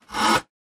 in_copingsaw_stroke_01_hpx
Coping saw cuts various pieces of wood. Tools, Hand Wood, Sawing Saw, Coping